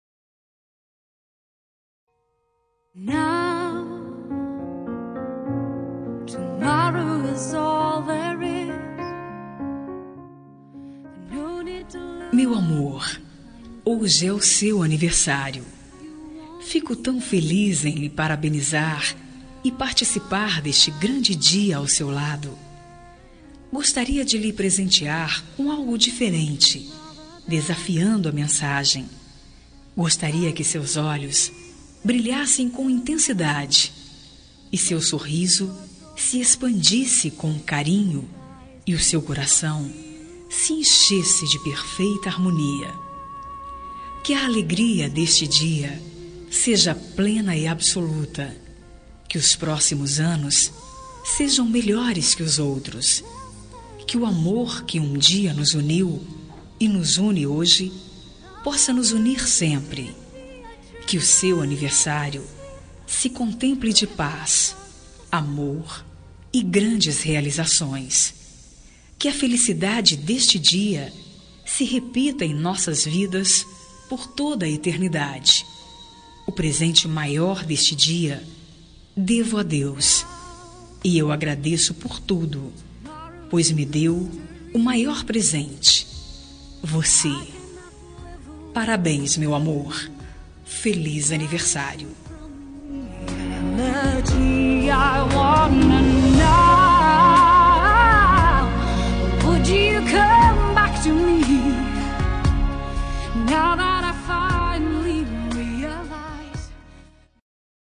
Telemensagem de Aniversário de Marido – Voz Feminina – Cód: 1147